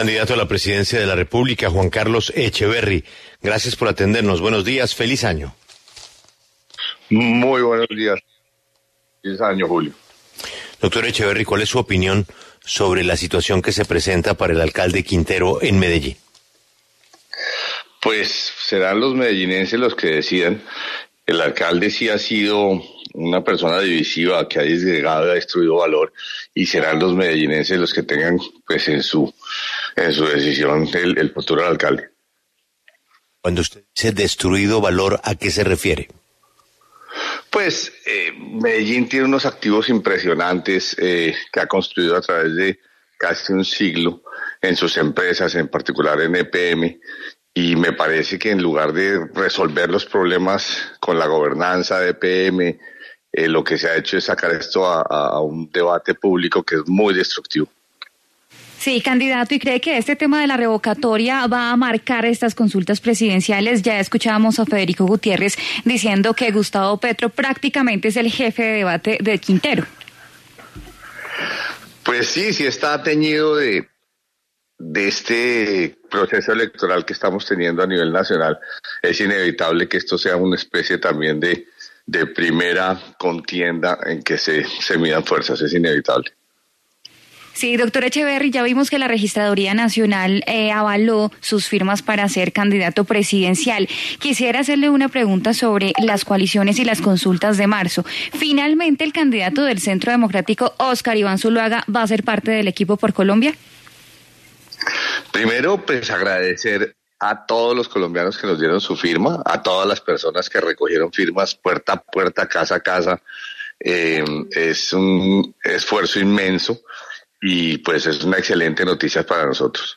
En diálogo con La W, el candidato presidencial Juan Carlos Echeverry se pronunció sobre el proceso para revocar al alcalde de Medellín, Daniel Quintero.